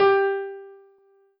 piano-ff-47.wav